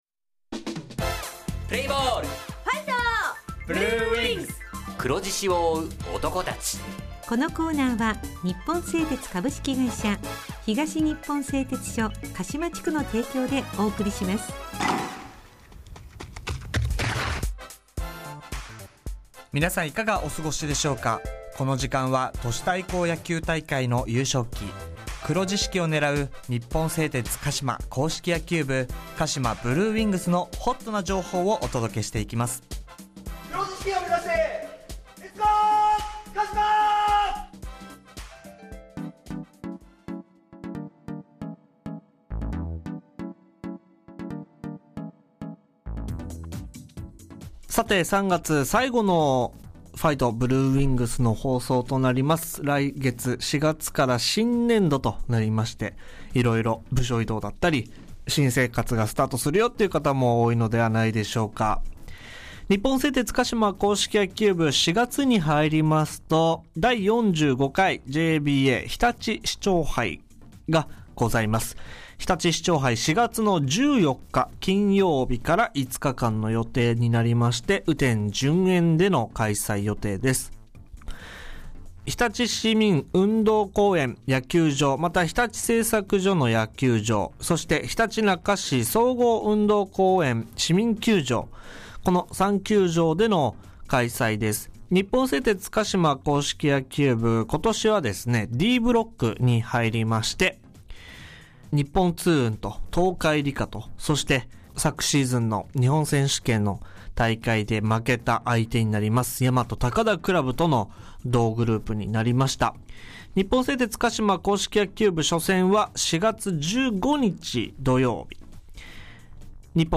地元ＦＭ放送局「エフエムかしま」にて当所硬式野球部の番組放送しています。